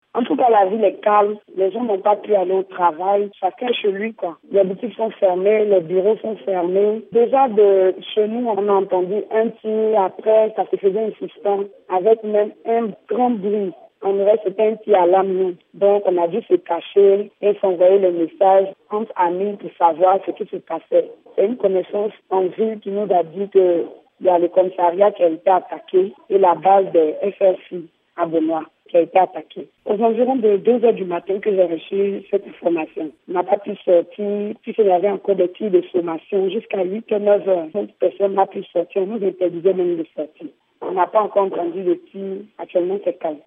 Une habitante de Bonoua